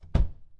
SFXs " 冷却器盖子关闭 02
描述：这是冷却器/冰箱关闭的记录。